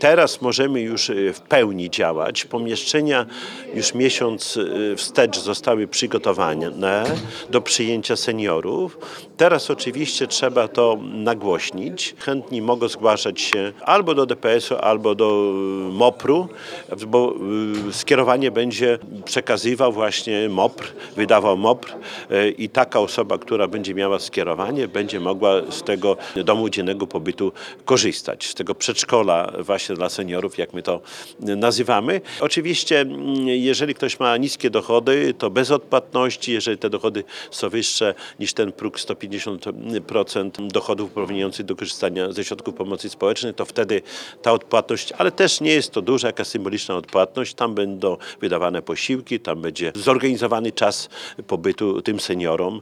Jak mówi Czesław Renkiewicz, prezydent miasta, będzie to swoiste przedszkole dla seniorów.